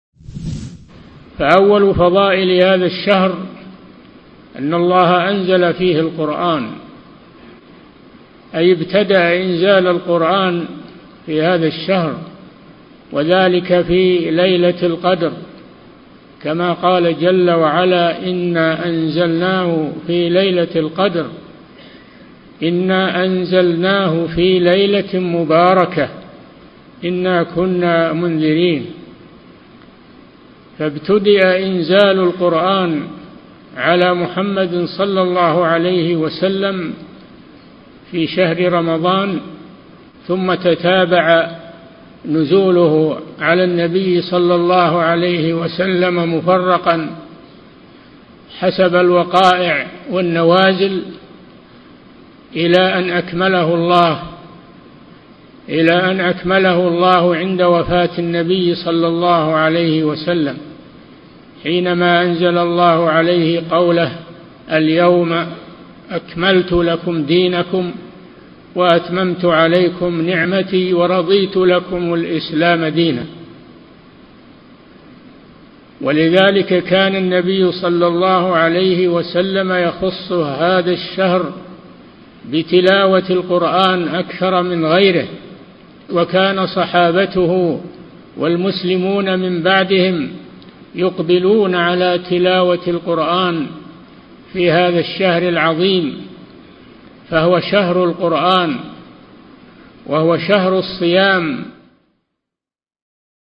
من مواعظ أهل العلم